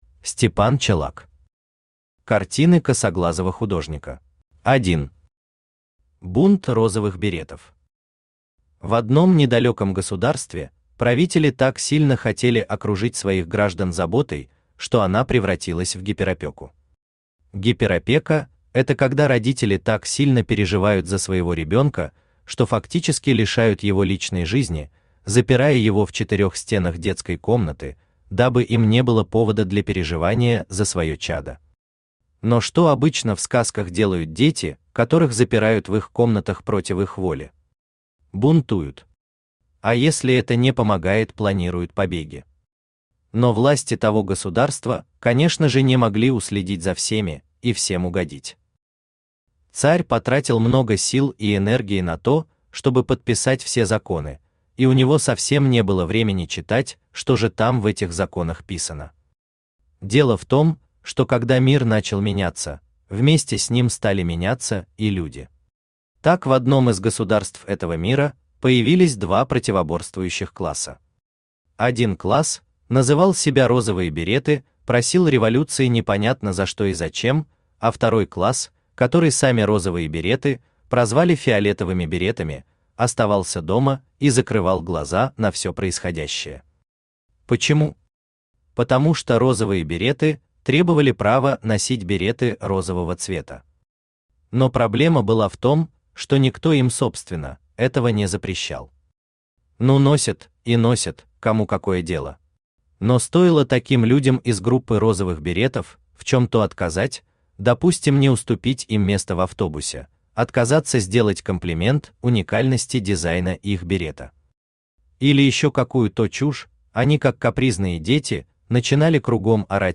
Аудиокнига Картины косоглазого художника | Библиотека аудиокниг
Aудиокнига Картины косоглазого художника Автор Степан Дмитриевич Чолак Читает аудиокнигу Авточтец ЛитРес.